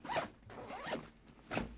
描述：拉开一个袋子